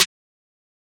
{Snare} Mafia3.wav